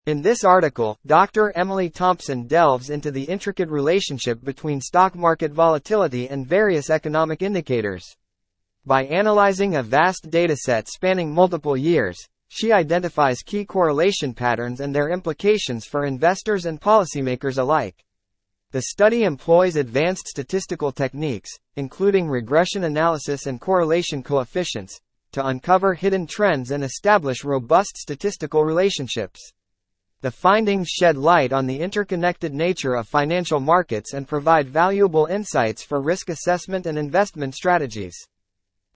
Free TTS